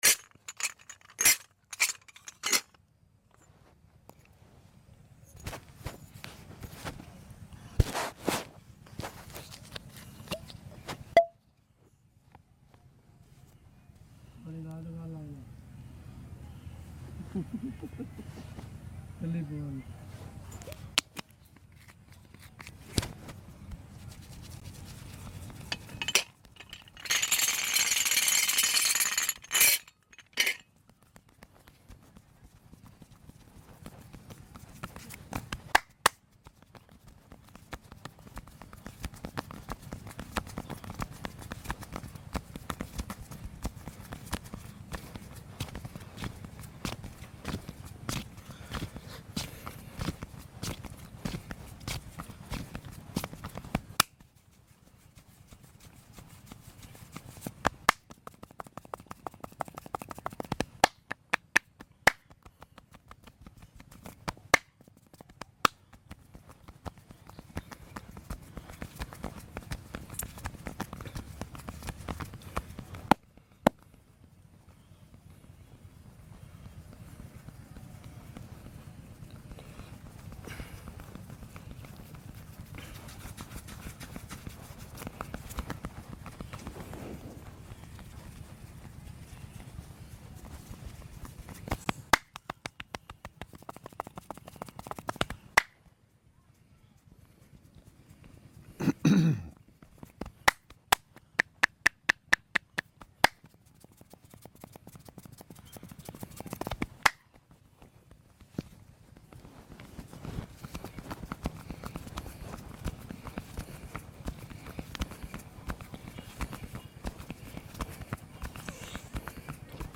$1 Relaxing ASMR Indian Head Sound Effects Free Download